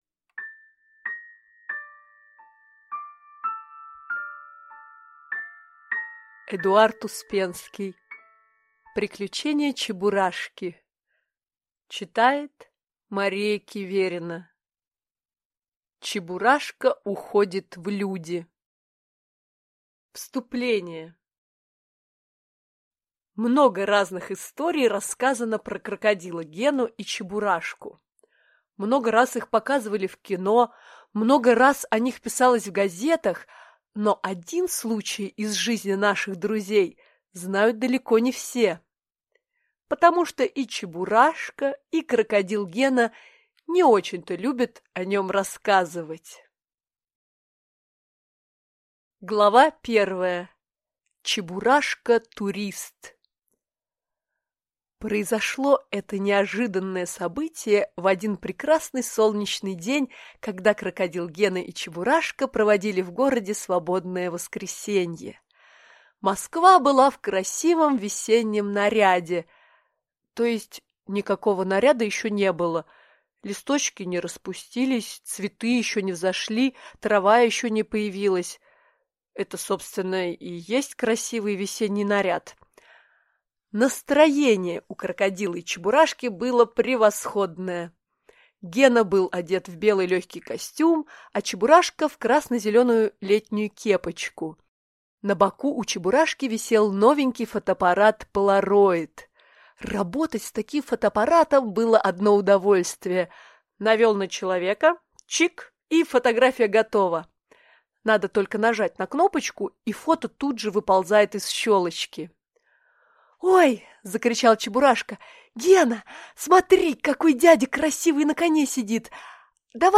Аудиокнига Приключения Чебурашки | Библиотека аудиокниг